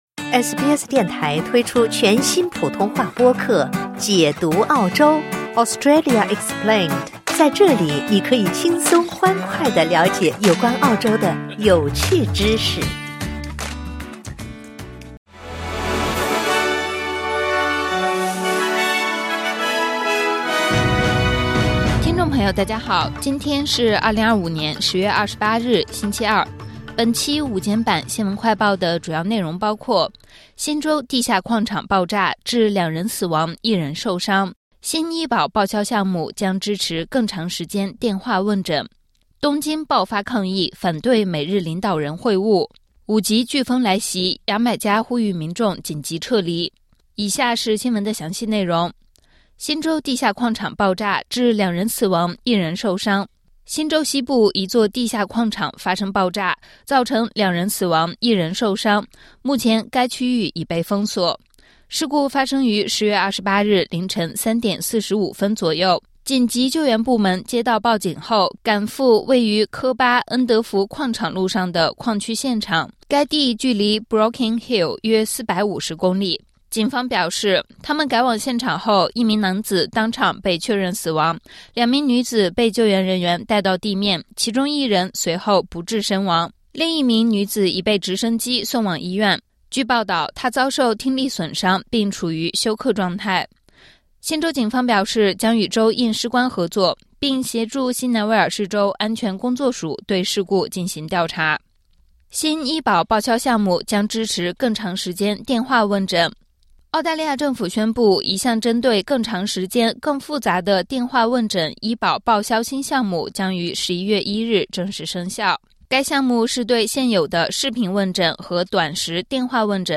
【SBS新闻快报】 新州地下矿场爆炸 两人死亡一人受伤